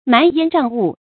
蛮烟瘴雾 mán yān zhàng wù
蛮烟瘴雾发音